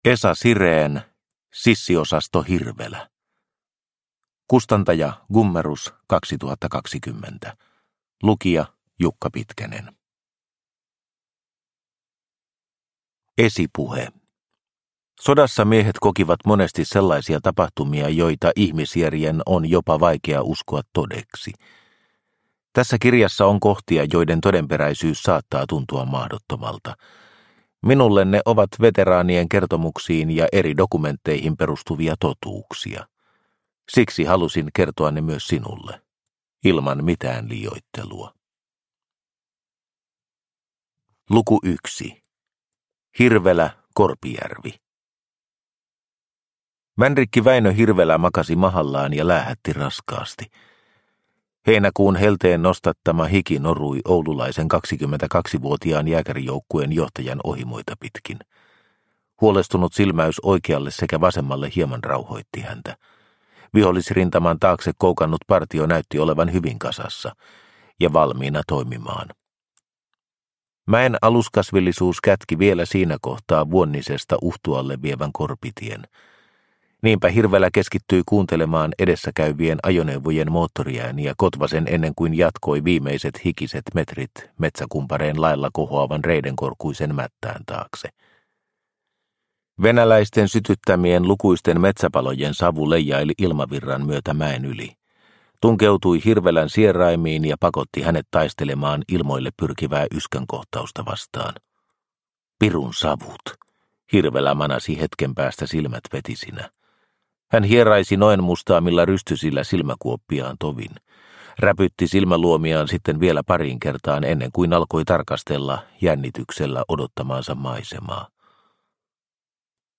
Sissiosasto Hirvelä – Ljudbok – Laddas ner